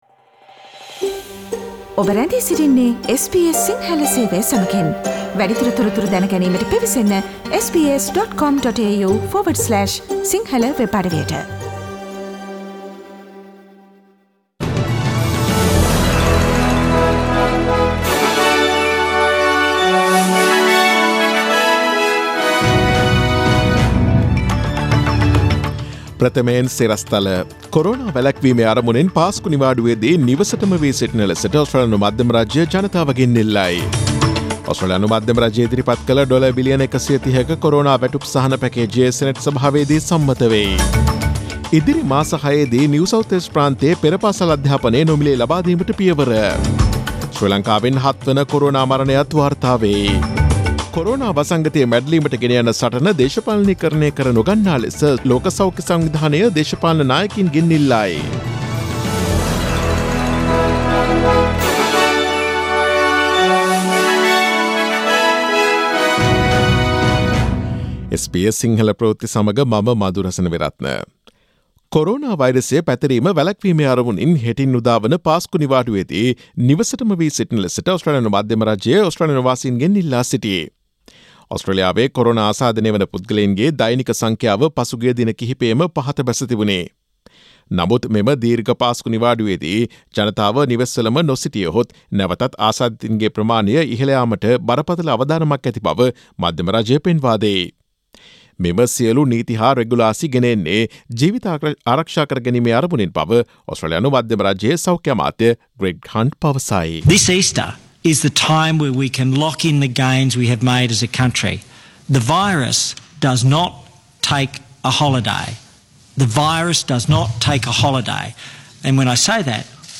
Daily News bulletin of SBS Sinhala Service: Thursday 09 April 2020